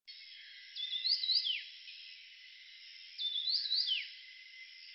19-4溪頭冠羽song1.mp3
冠羽畫眉 Yuhina brunneiceps
錄音地點 南投縣 鹿谷鄉 溪頭
錄音環境 森林
行為描述 鳴唱
標籤/關鍵字 鳥